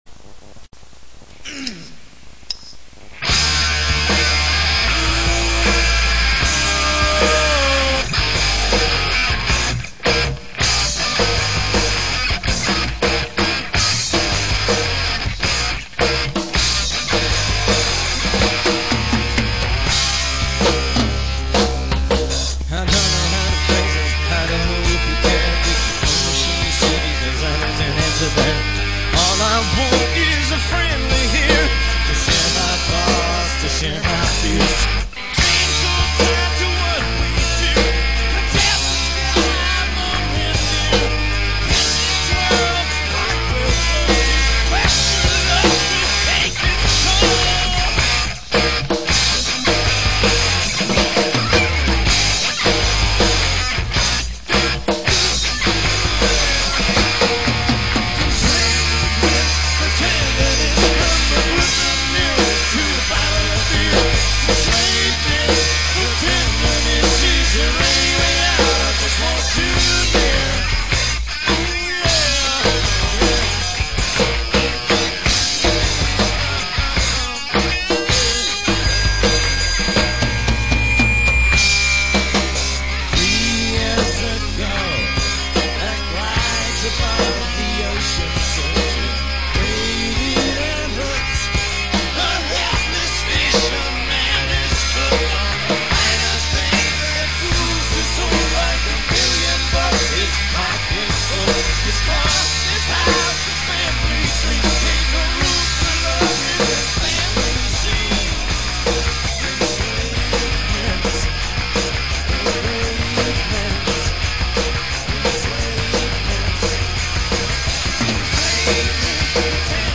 The vocals are a bit week on this track.